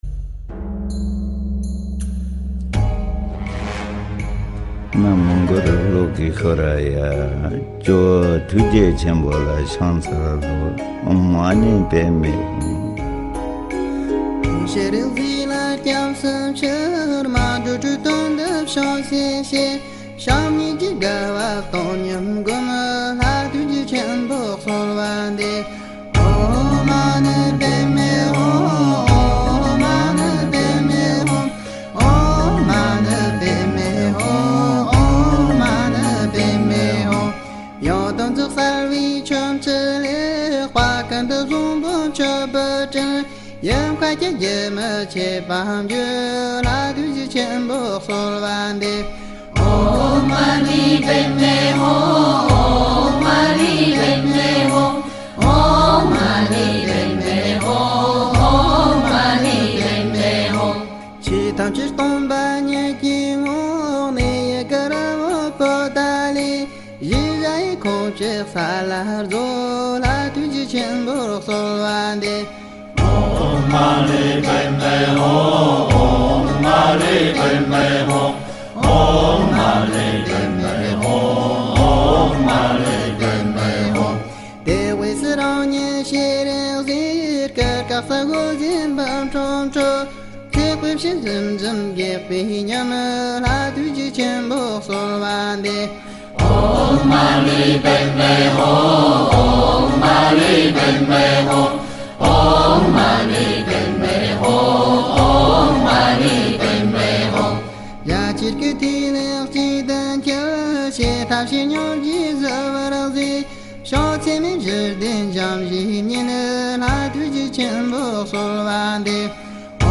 《大悲六字真言急速加持祈請頌》(法王藏文版 - 只有唱誦).mp3